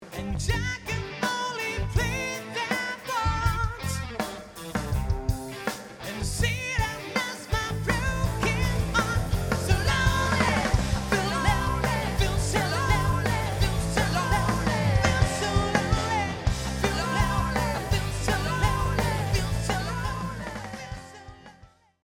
encore deux exemples d'un truc un peu plus rock que j'avais fait il y a deux semaines: console M7CL (tout dans la console) et comme on l'entend bien, déjà une bonne dose de compression sur le chant (également pas mal sur la basse en prise DI... mais rien sur la batterie); du reste ça a été mixé et enregistré à -15dB
son d'origine de l'enregistrement (si c'est pas assez fort pour vous, montez le volume de votre chaine.....)